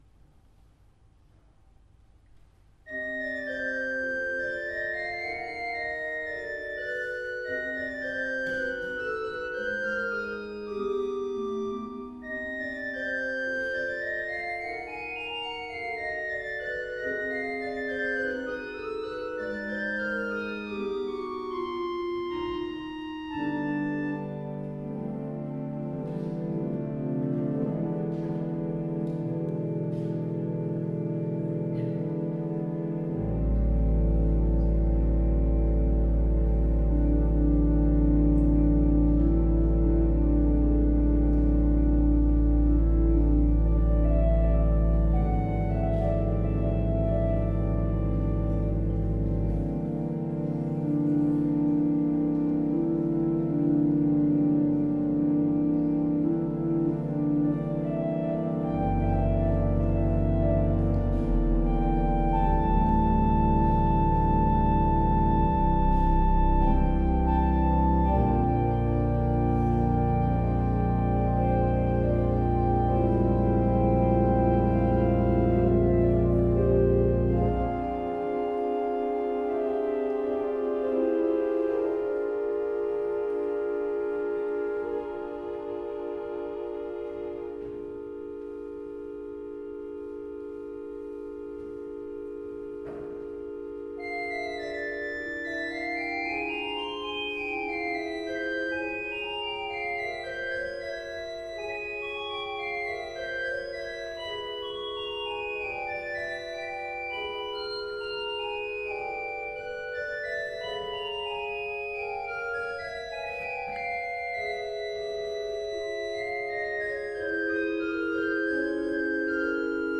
Ålands orgelfestival 2016 - inspelningar
orgel